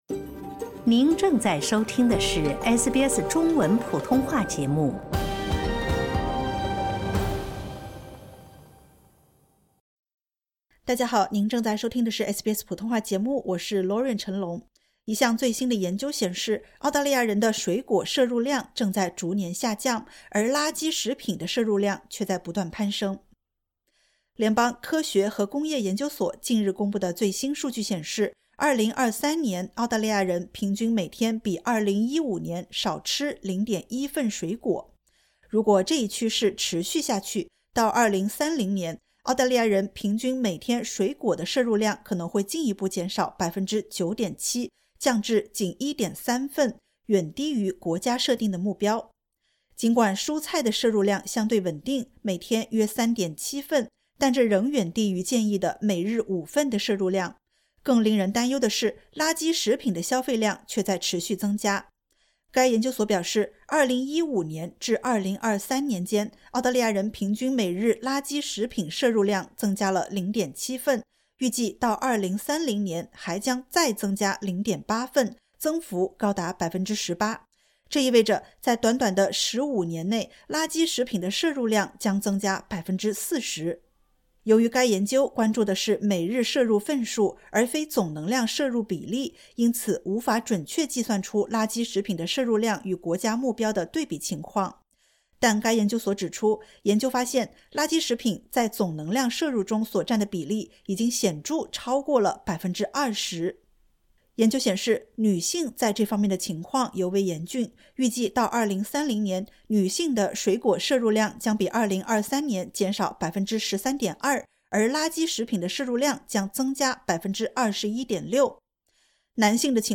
一项最新研究显示，澳大利亚人的水果摄入量正在逐年下降，而垃圾食品的摄入量却在不断攀升。点击 ▶ 收听完整报道。